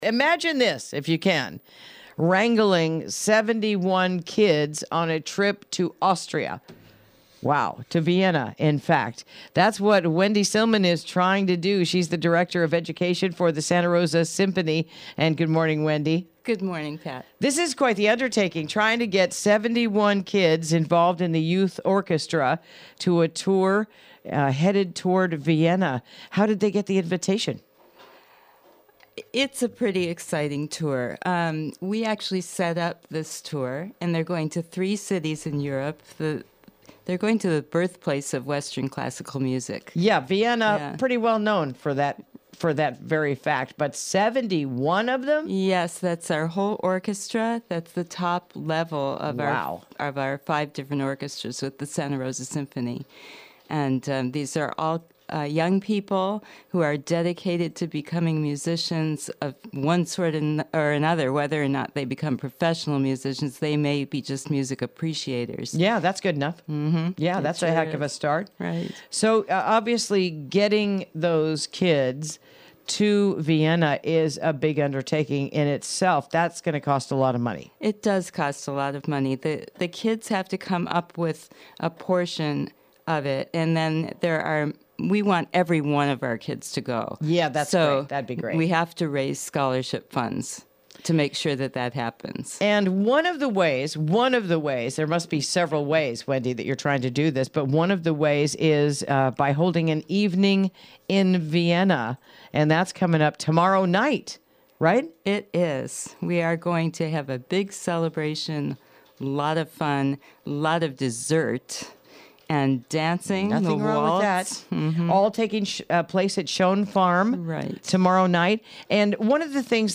Interview: The 2018 Youth Orchestra Tour with the Santa Rosa Symphony